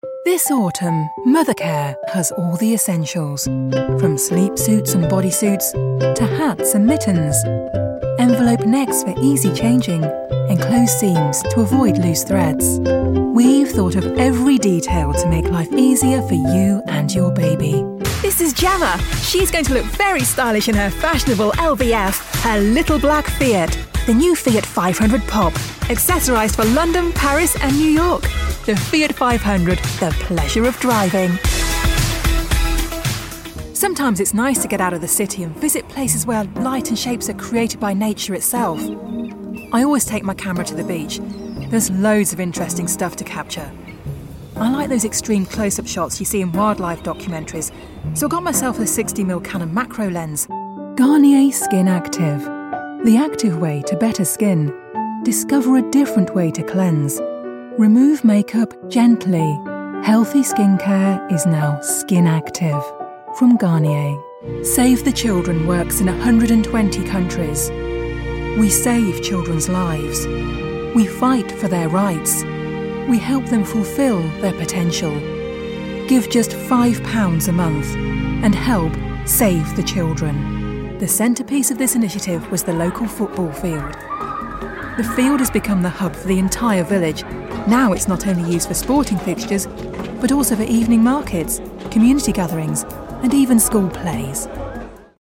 Female
Assured, Authoritative, Confident, Corporate, Engaging, Friendly, Gravitas, Natural, Reassuring, Smooth, Soft, Warm, Witty, Conversational
Microphone: Neumann TLM103